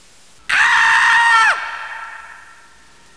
Scream 4.wav